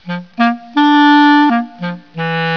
souffler,
souffler.mp3